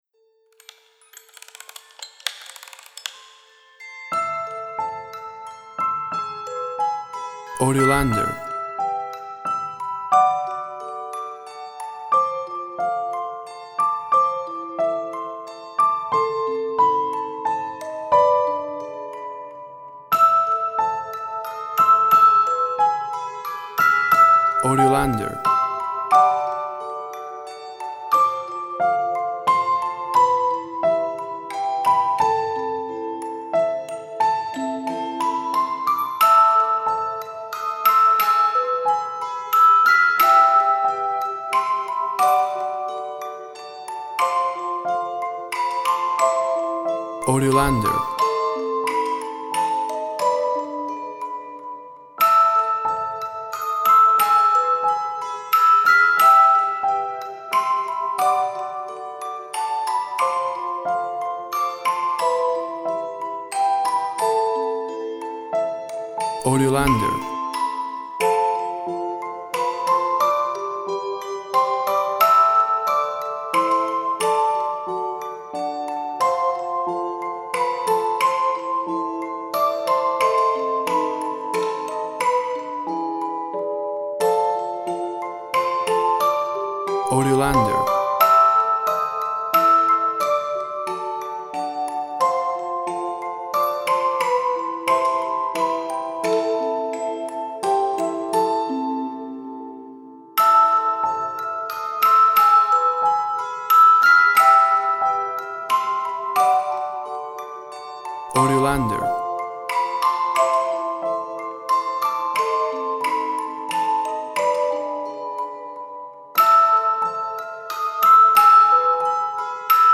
WAV Sample Rate 24-Bit Stereo, 44.1 kHz
Tempo (BPM) 90